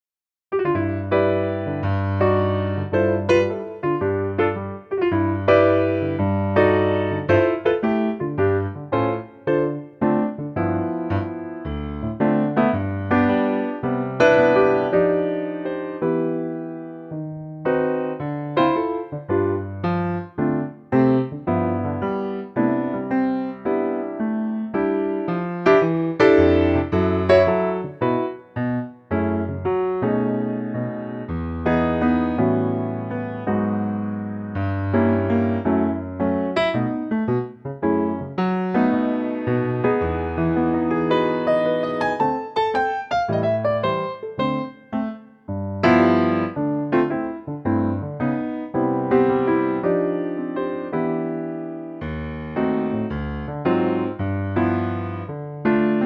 Unique Backing Tracks
key - C - vocal range - G to A